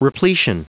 Prononciation du mot repletion en anglais (fichier audio)